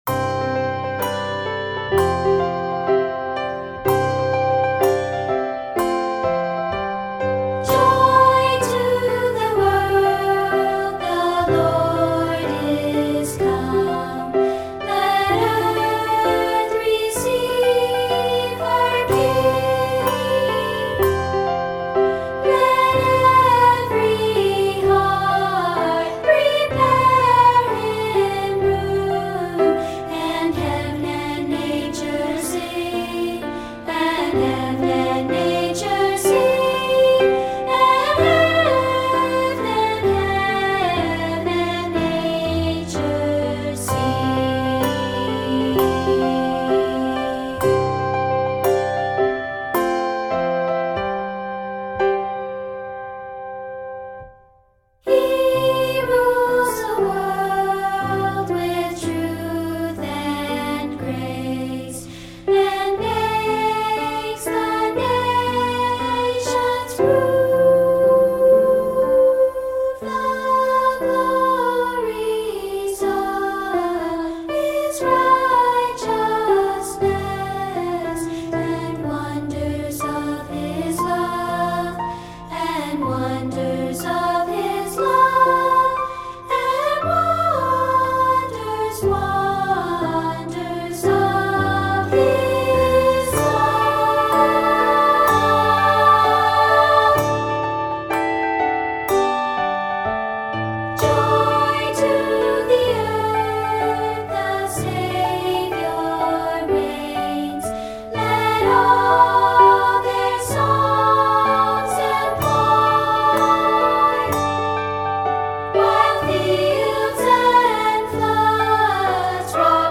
Brilliant and bursting with joy